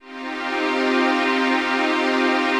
ATMOPAD15.wav